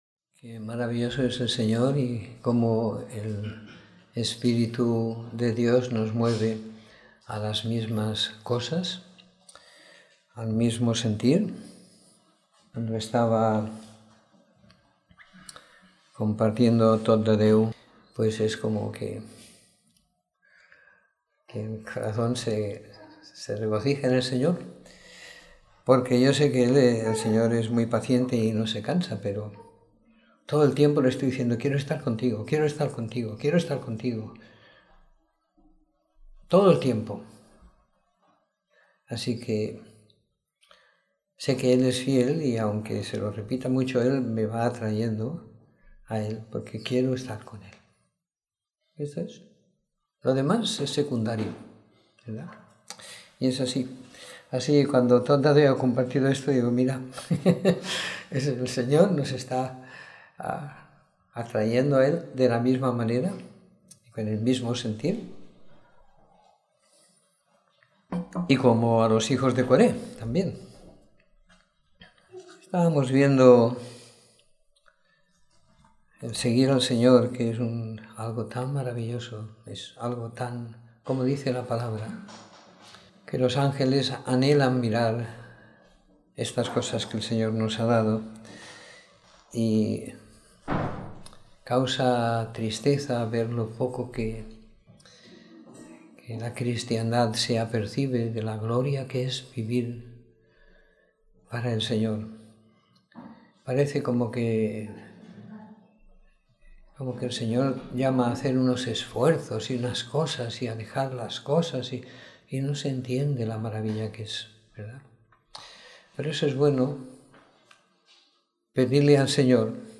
Reunión de Domingo por la Tarde